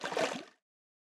PenguinSplash-004.wav